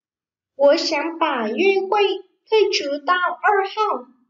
Gủa xéng bả duê huây thuây chứ tao ơ hao.